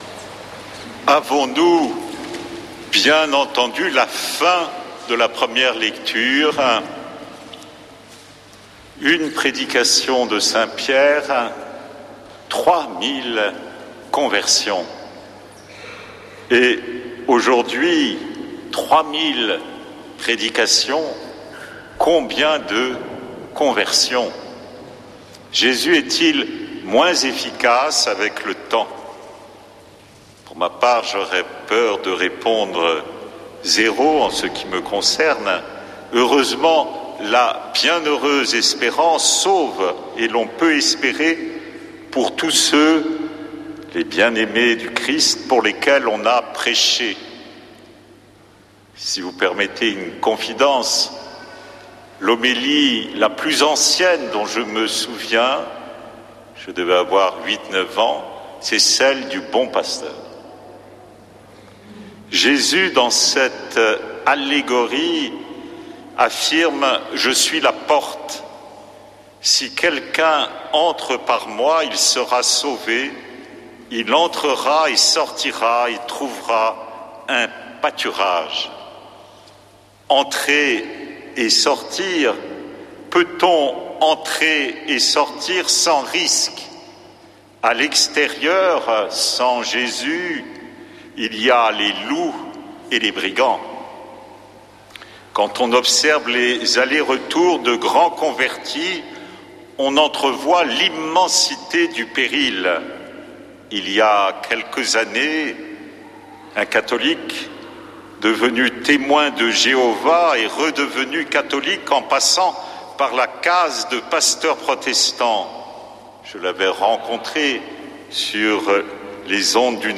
Messe depuis le couvent des Dominicains de Toulouse du 26 avr. - Radio Présence
Frères de la communauté